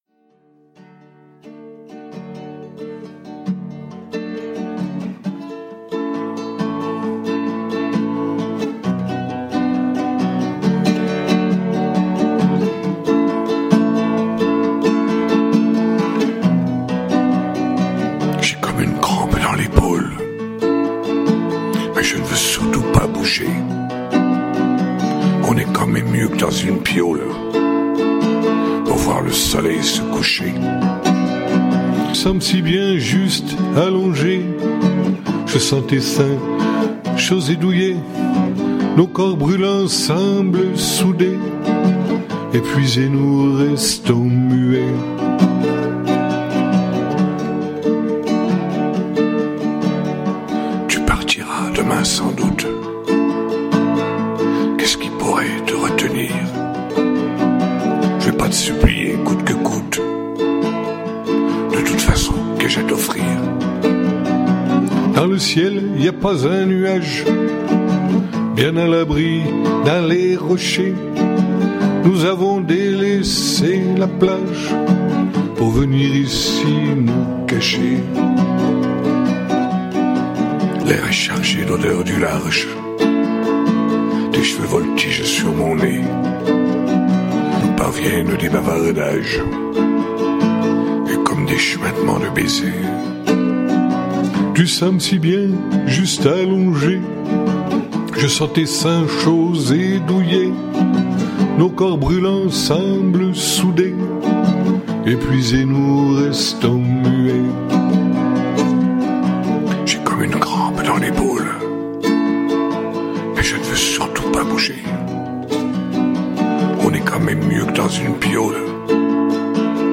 [Capo 2°]